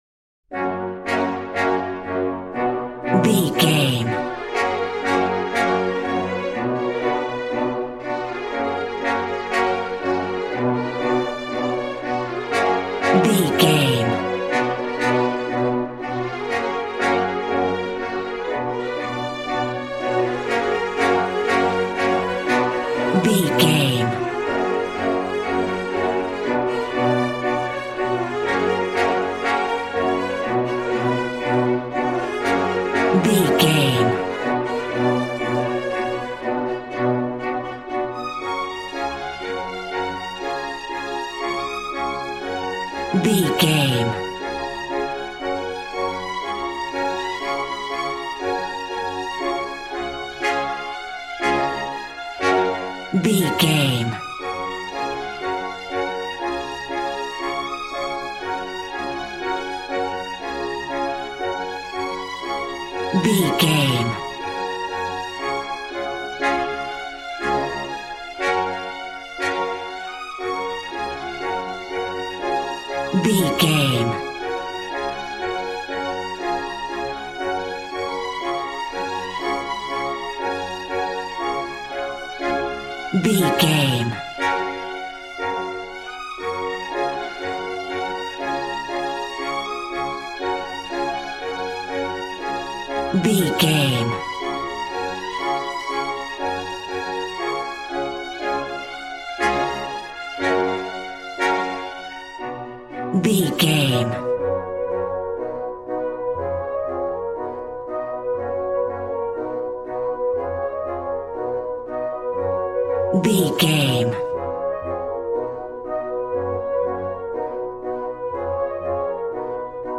Ionian/Major
G♭
cheerful/happy
joyful
drums
acoustic guitar